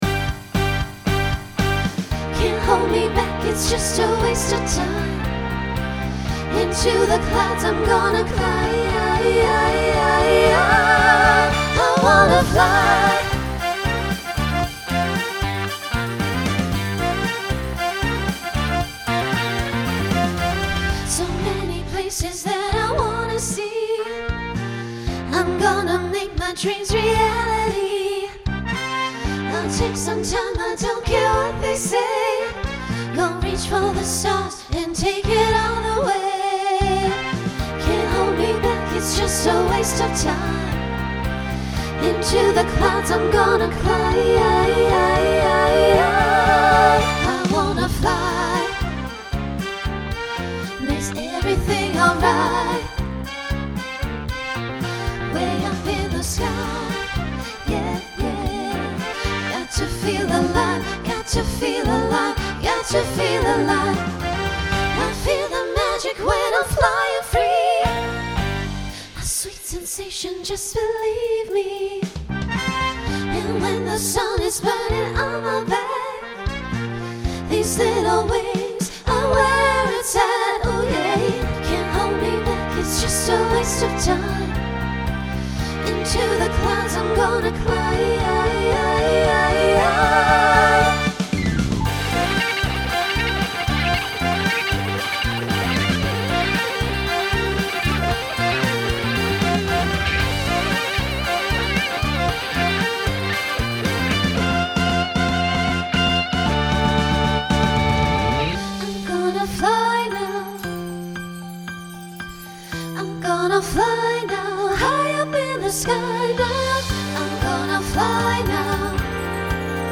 Opener Voicing SSA